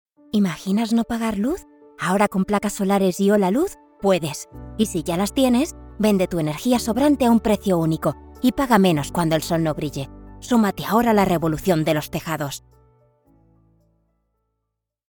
Comercial, Joven, Natural, Versátil, Suave
Comercial